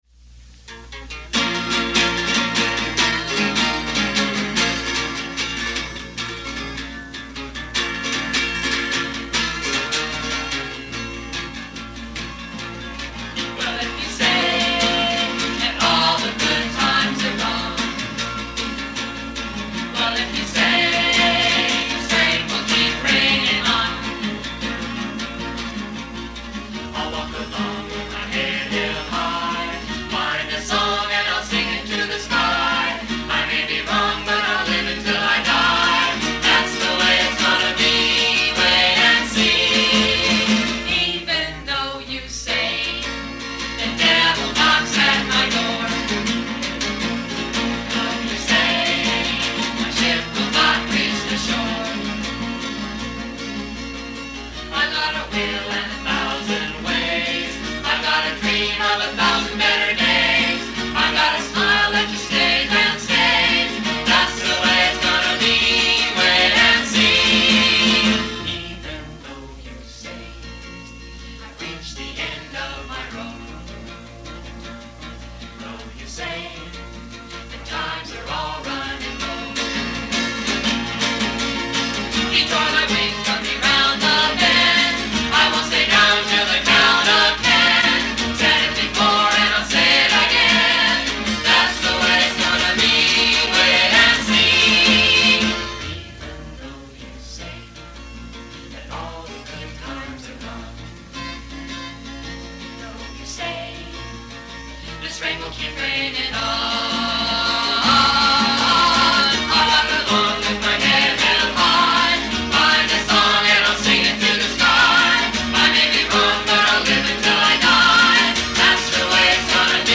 Recorded live when we were 15-17 years old